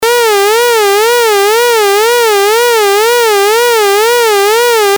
pic 12a) and b) A saw waveform a)nonmodulated and b) pitchmodulated by an lfo with a sine waveform.
pitchlfo_saw.mp3